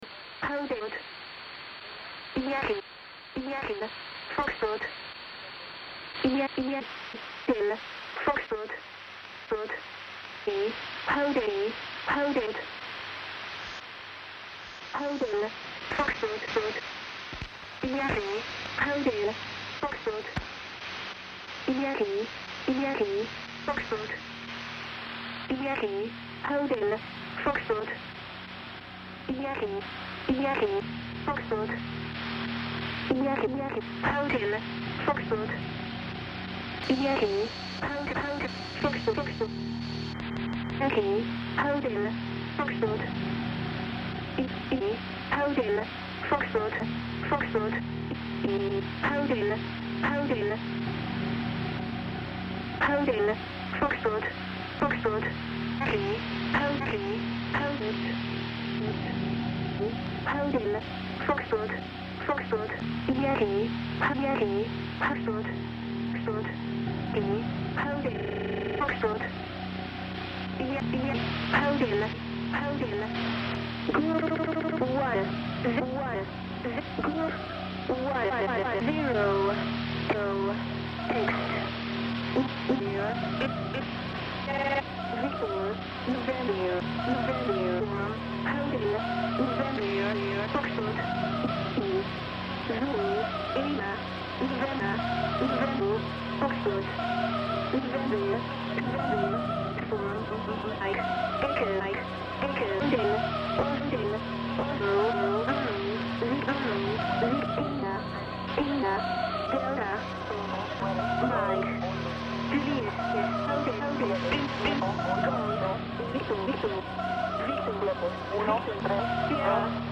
Book party for Transmission Arts: Artists & Airwaves: Jul 23, 2011: 6pm - 8pm
Live on WGXC 90.7-FM at Spotty Dog Books & Ale.